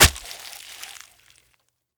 Flesh Chop Sound
horror